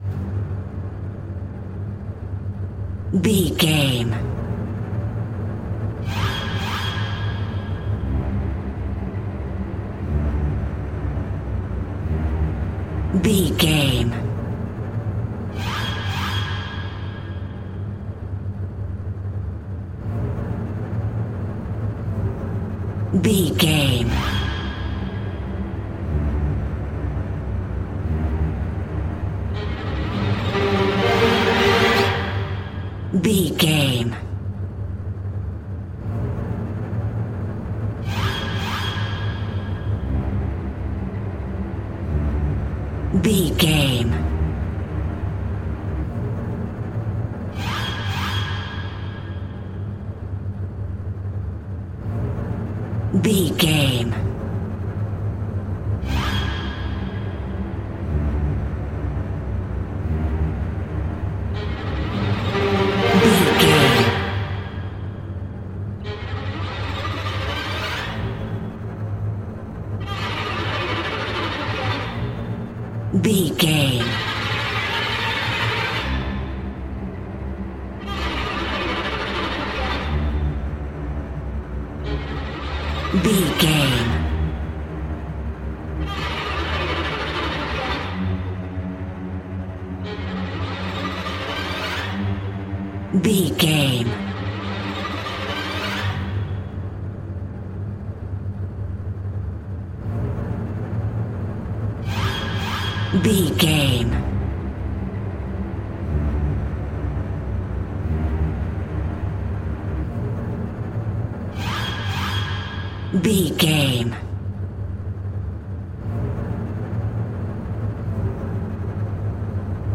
Aeolian/Minor
tension
ominous
dark
haunting
eerie
synth
ambience
pads